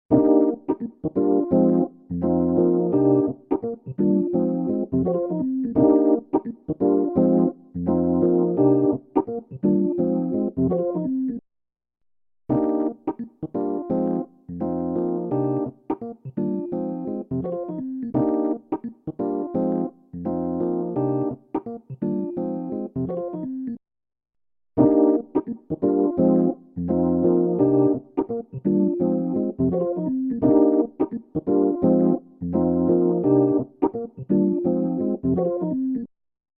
渦巻くクラシック・ロータリーサウンド
Rotary Mod | Rhodes | Preset: Slowly Rotate
Rotary-Eventide-Rhodes-Slowly-Rotate-Mix60.mp3